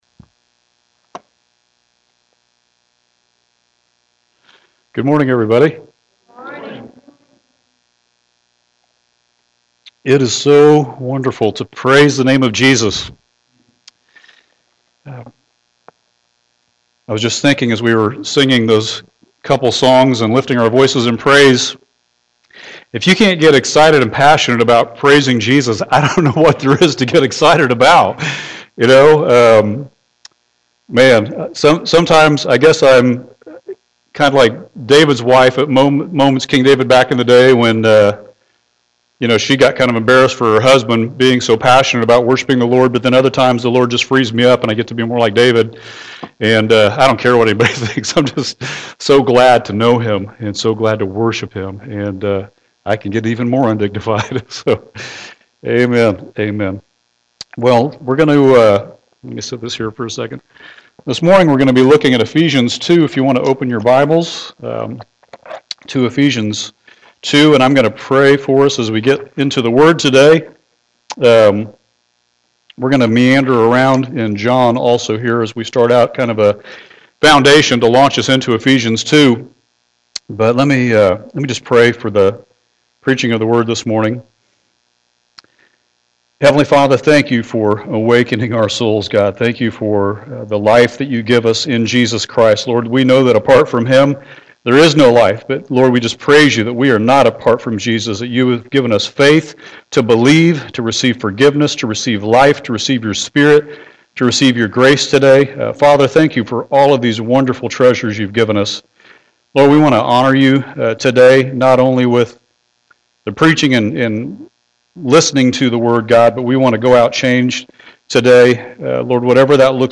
During our worship services on Sunday mornings you will hear the preaching and teaching of God’s Word.